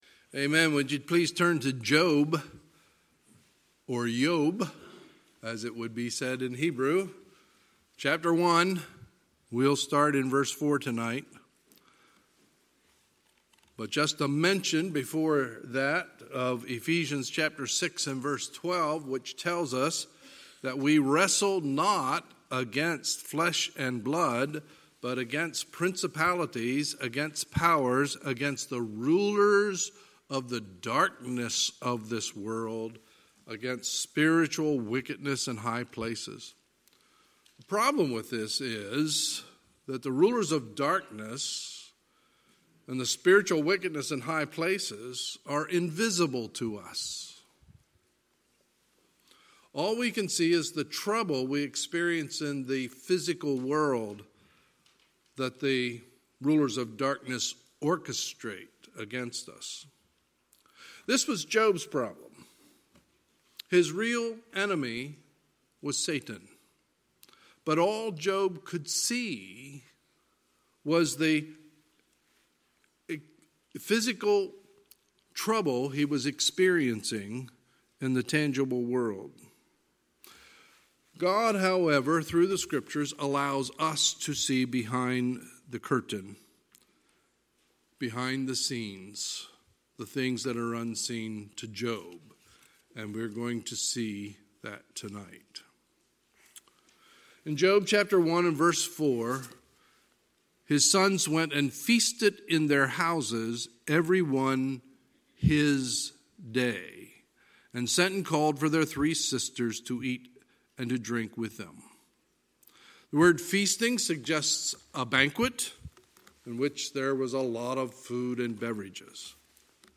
Sunday, January 12, 2020 – Sunday Evening Service